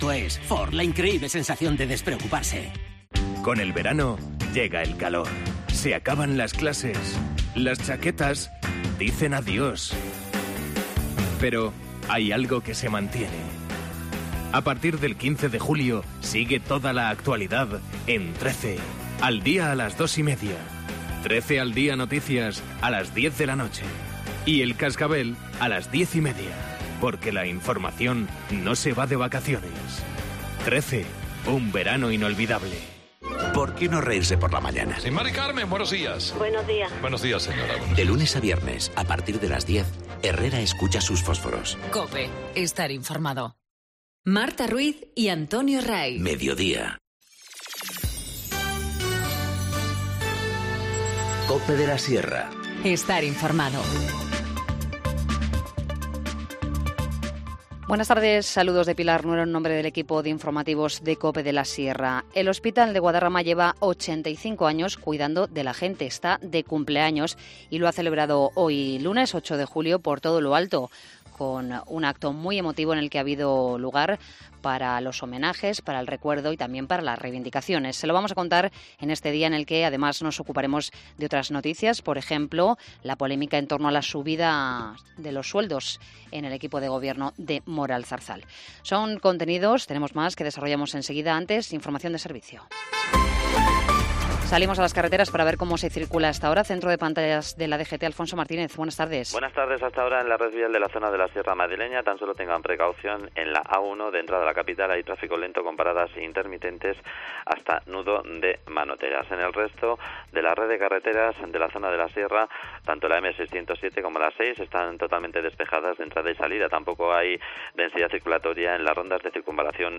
Informativo Mediodía 8 julio 14:20h